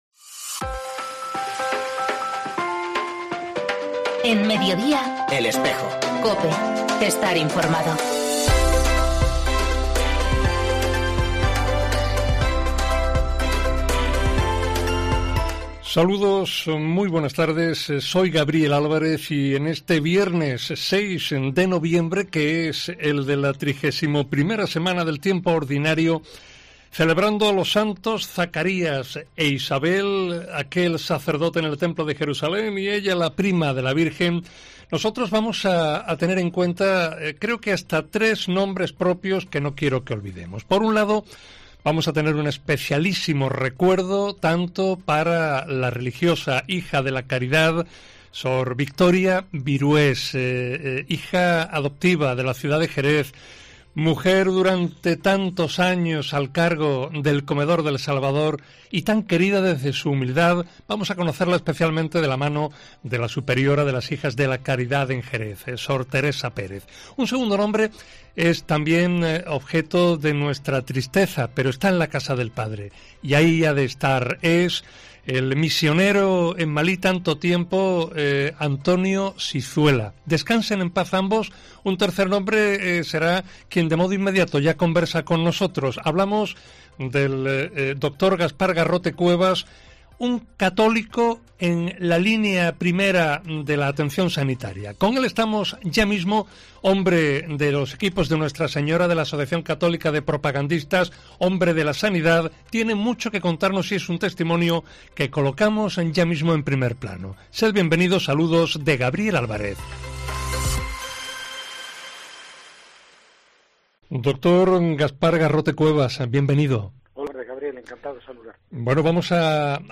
La entrevista realizada en El Espejo de Asidonia-Jerez de este viernes subraya su pensamiento al respecto.